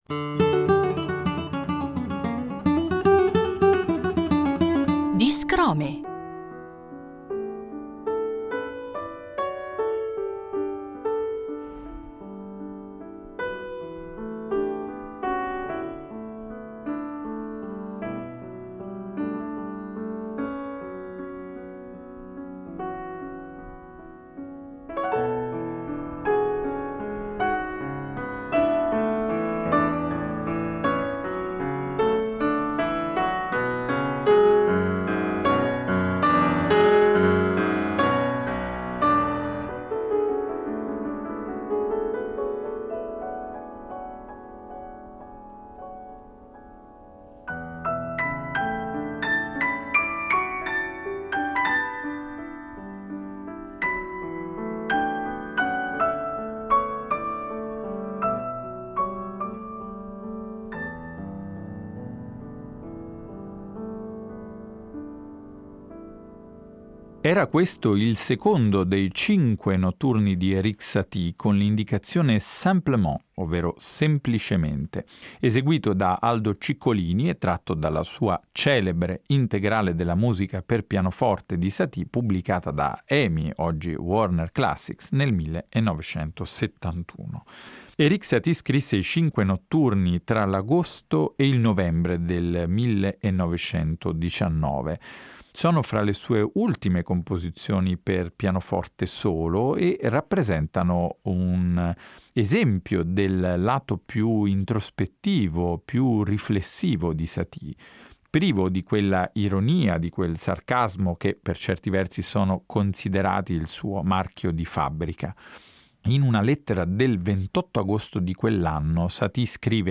il pianista e produttore discografico